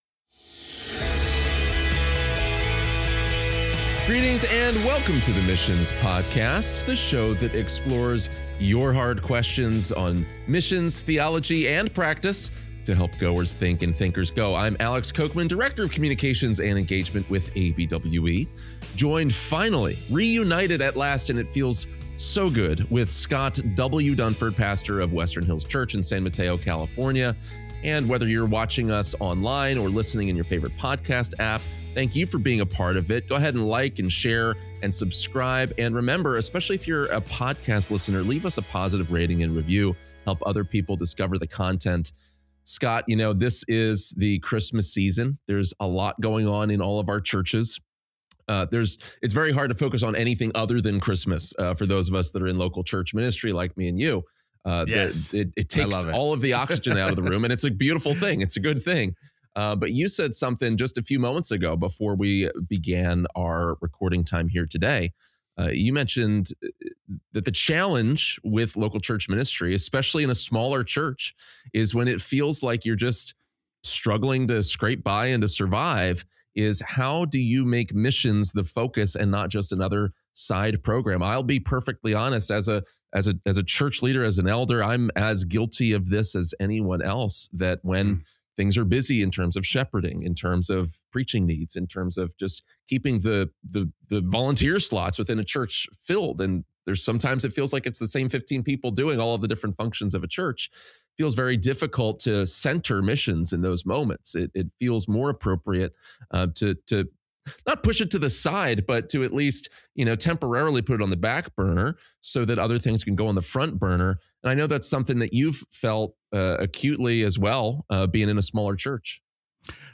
They emphasize that preaching, prayer, discipleship, and the ordinances—when practiced consistently—form the foundation through which God raises up and sends missionaries to the nations. The conversation highlights how this church-centered approach applies across cultures, including in locations with limited resources and growing opposition to Christianity.